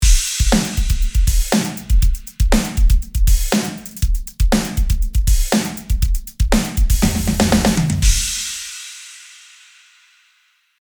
さっそく使ってみようということで、こちらのドラムトラックに “Replika XT” を挿します。
プリセットを見てみると “Drums” フォルダがあったので、その中の “Drums Phat” を選んでみます。
キックが重く、空間が広くなったような感じがします。